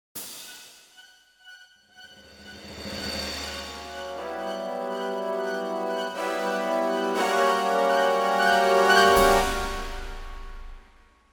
Colonne sonore che fanno parte del gioco.